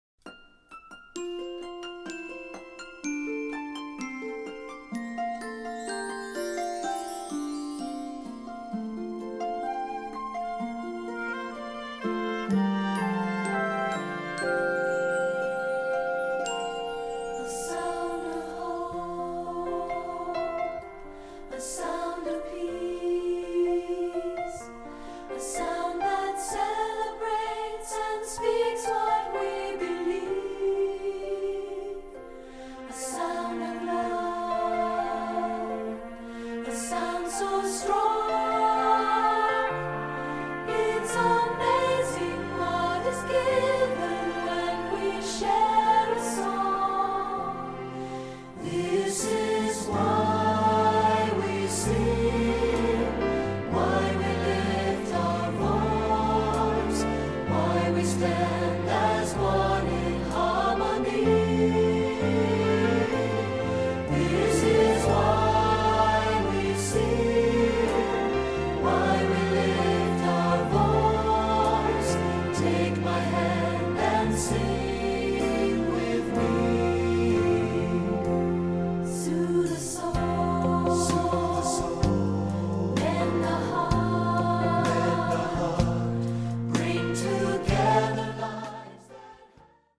SATB
Choral Concert/General
SAB